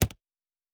pgs/Assets/Audio/Sci-Fi Sounds/Interface/Click 5.wav at 7452e70b8c5ad2f7daae623e1a952eb18c9caab4
Click 5.wav